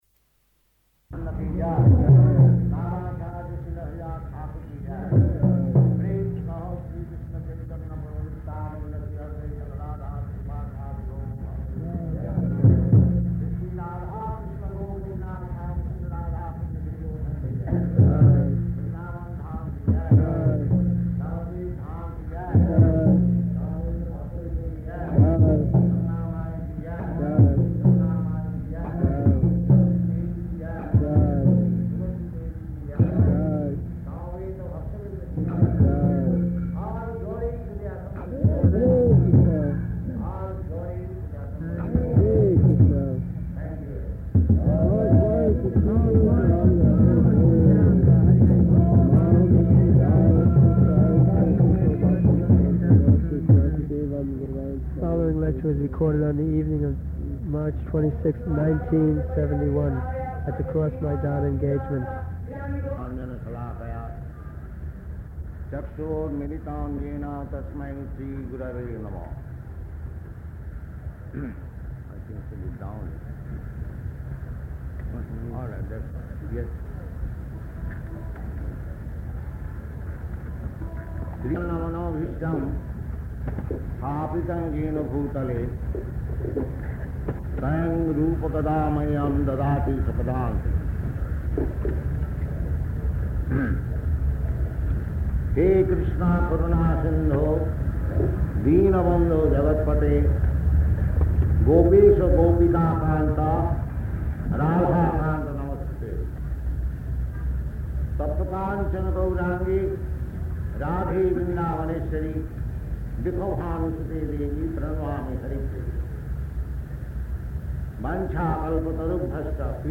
Type: Lectures and Addresses
The following lecture was recorded on the evening of March 26th, 1971, at the Cross Maidan engagement.